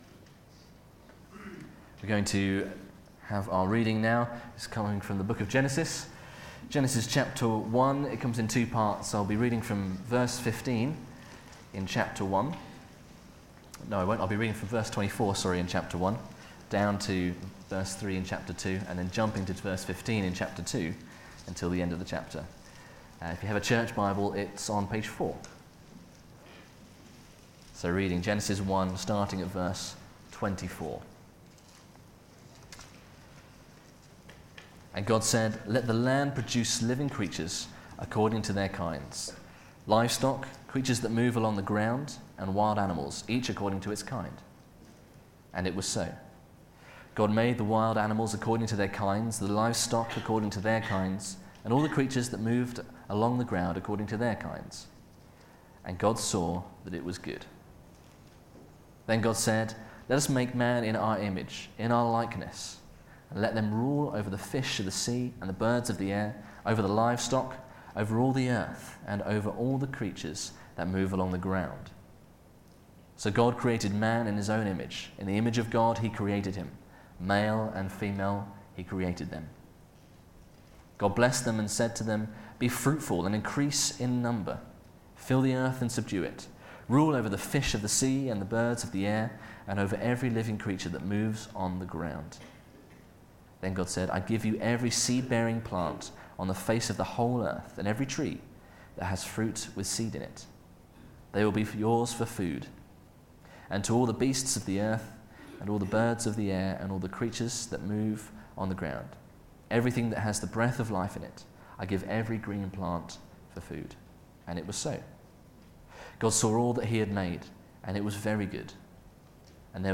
Media for Sunday Service
Theme: Sermon